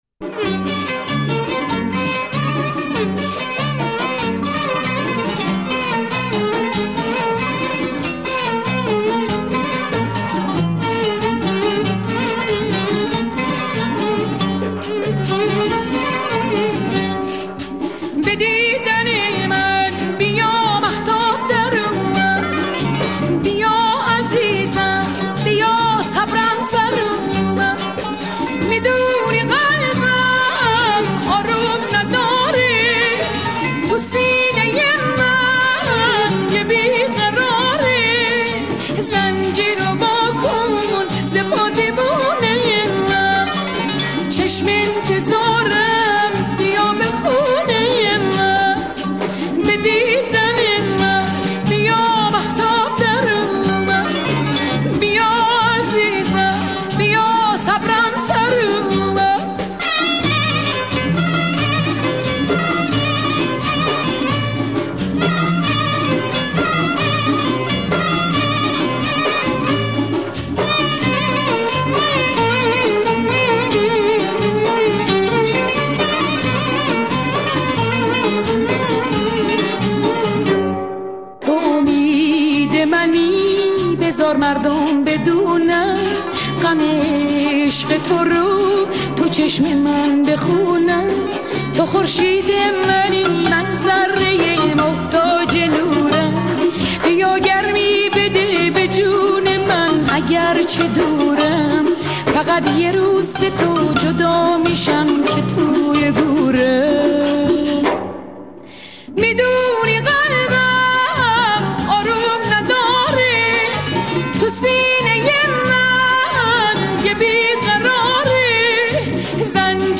Ba Sadaye Zan